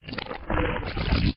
Corrosion1.ogg